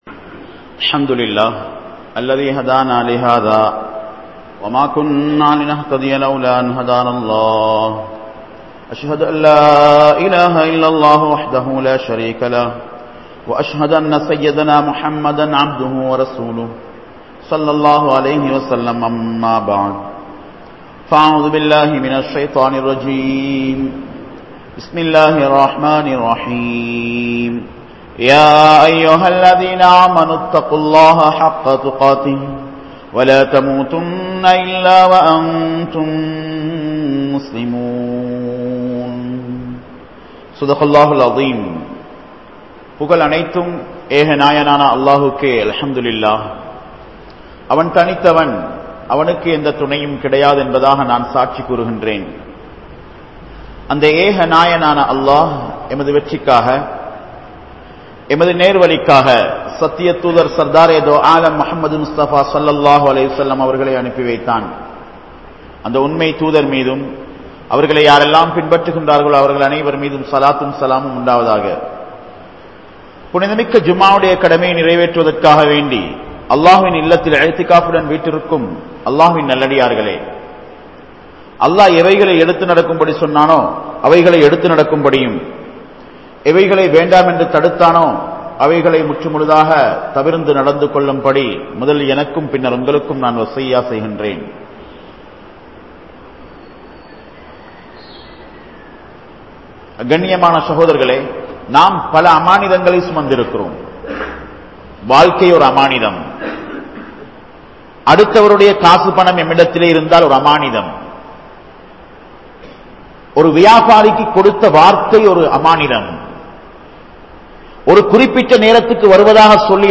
Petroarhalin Muhaththil Kari Poosum Pengal (பெற்றோர்களின் முகத்தில் கரி பூசும் பெண்கள்) | Audio Bayans | All Ceylon Muslim Youth Community | Addalaichenai